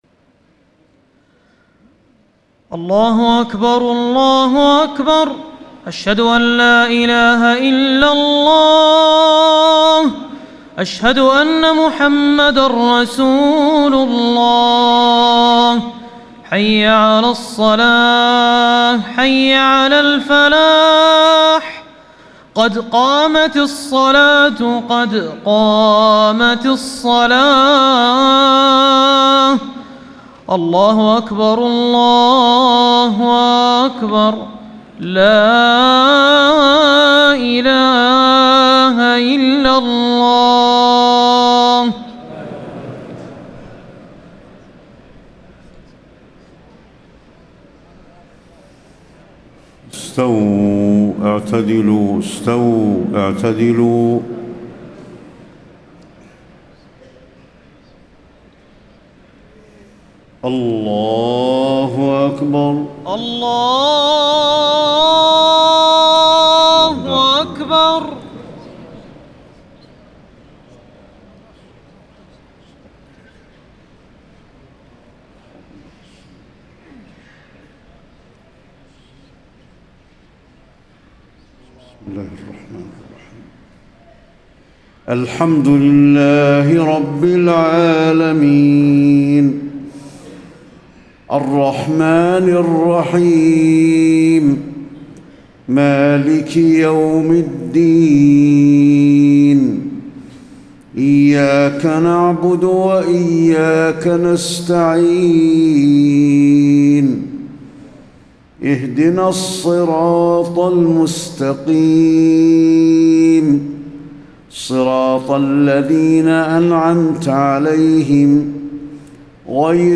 صلاة العشاء 1-9-1434 من سورة المعارج > 1434 🕌 > الفروض - تلاوات الحرمين